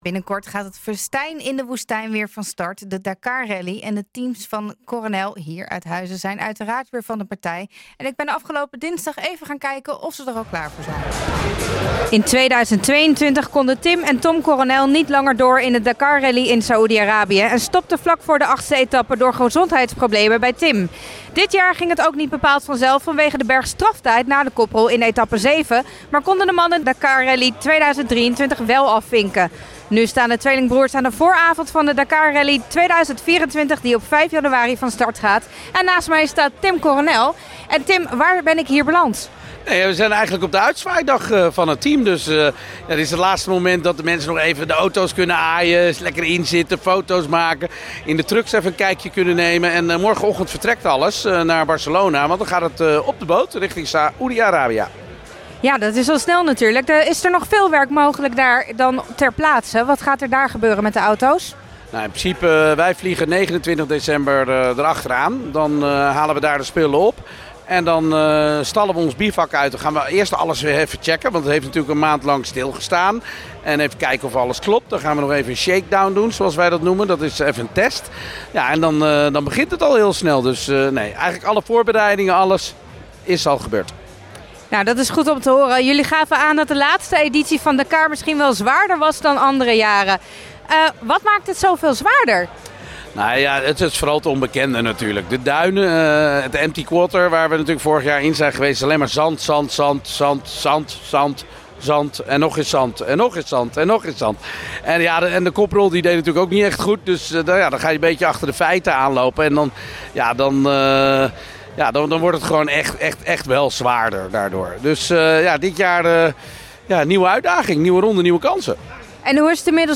Interview Tim Coronel tijdens het uitzwaai moment naar de Dakar Rally
nh-gooi-zaterdag-interview-coronel-tijdens-uitzwaai-moment-naar-dakar-rally.mp3